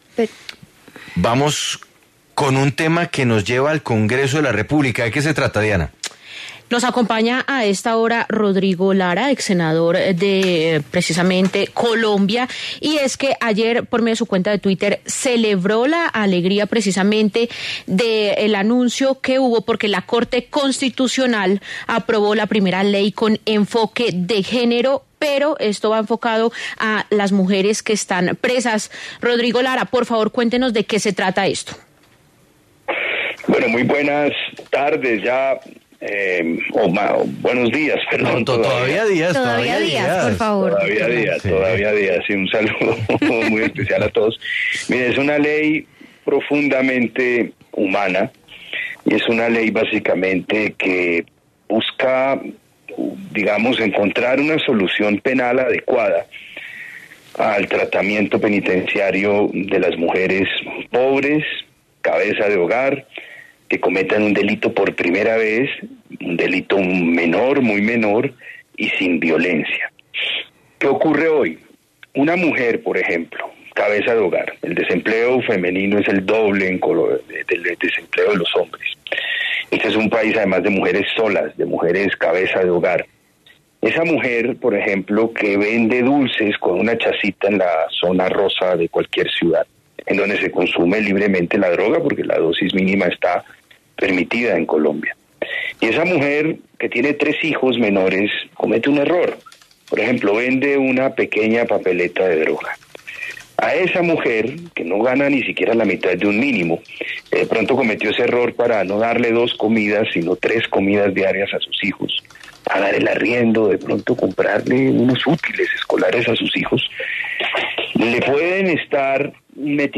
En diálogo con La W, el senador Rodrigo Lara se pronunció sobre la ley que beneficiaría a mujeres cabeza de hogar de bajos recursos.